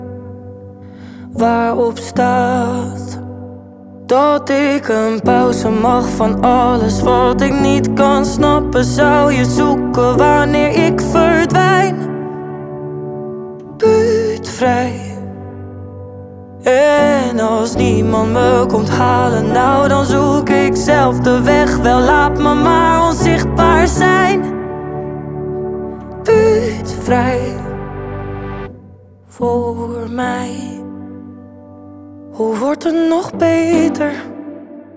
2025-09-11 Жанр: Поп музыка Длительность